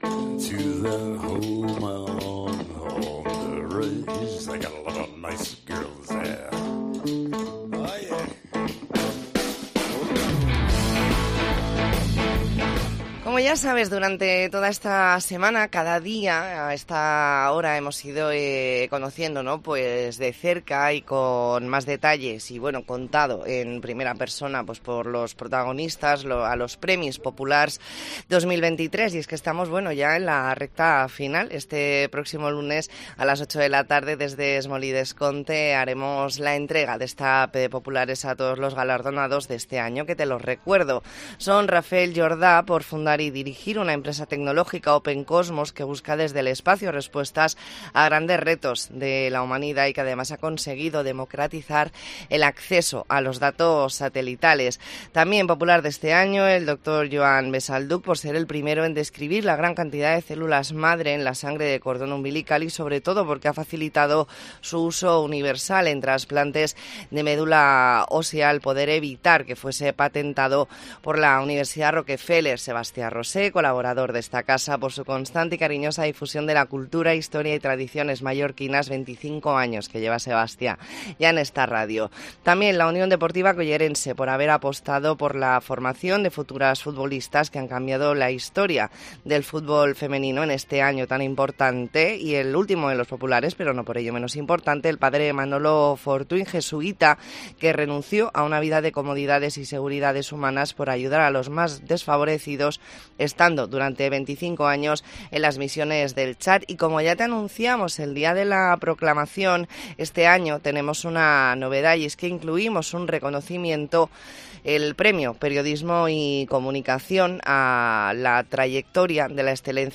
E ntrevista en La Mañana en COPE Más Mallorca, viernes 24 de noviembre de 2023.